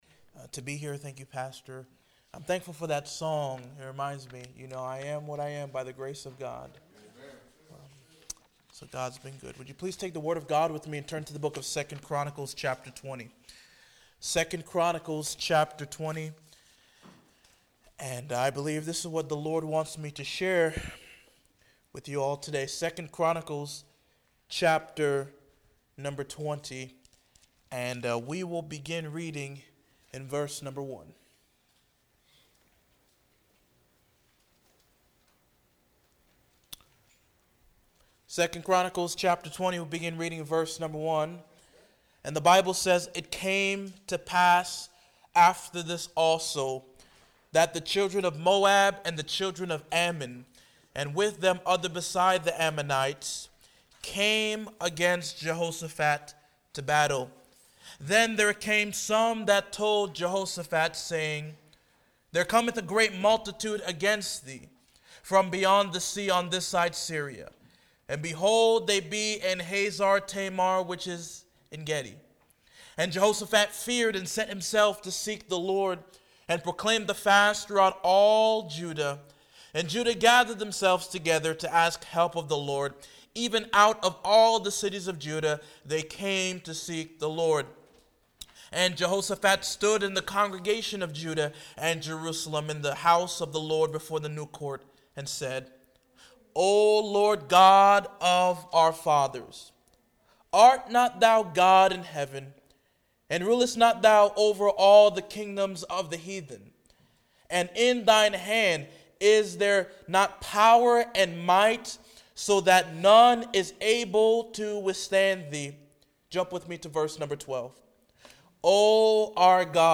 Guest speaker and missionary